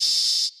Open Hat [ Murda On Top ].wav